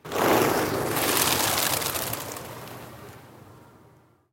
Звуки полета птиц
Звук улетающих воробьев и других мелких птиц